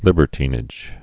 (lĭbər-tēnĭj)